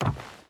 Footsteps / Wood / Wood Run 2.wav
Wood Run 2.wav